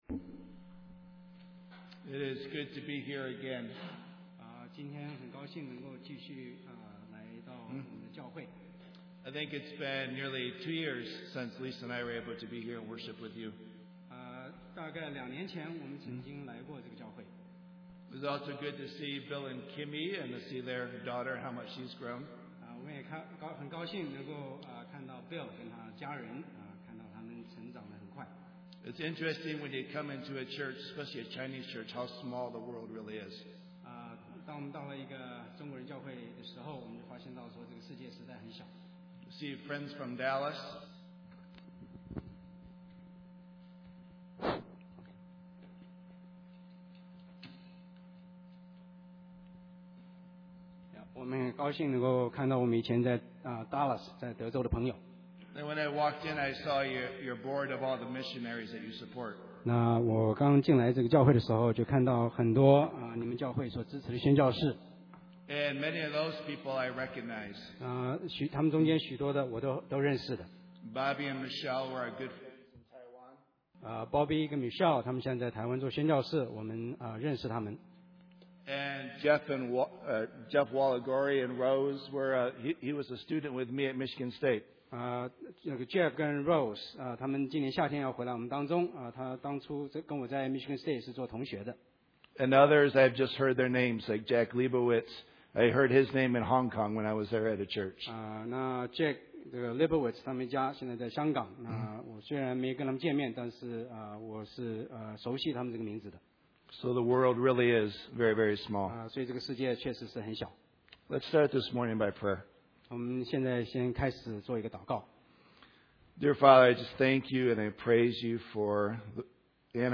英文講道 – 第 21 頁 – 安城華人基督教會